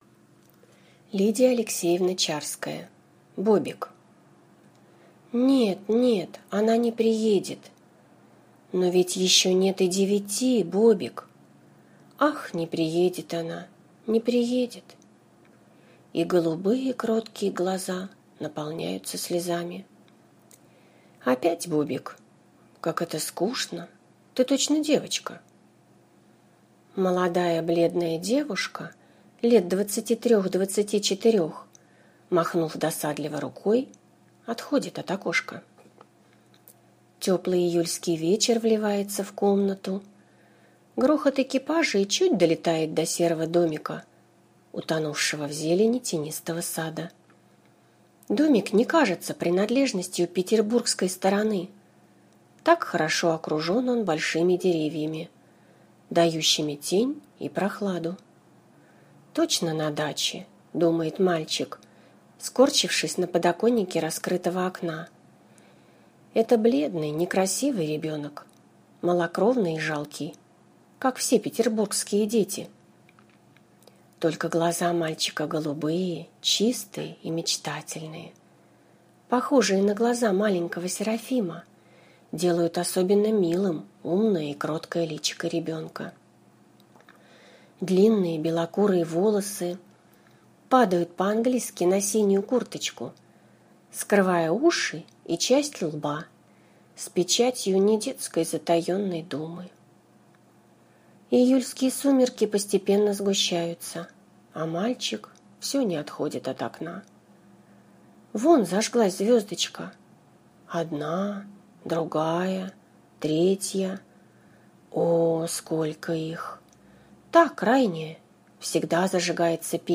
Аудиокнига Бобик | Библиотека аудиокниг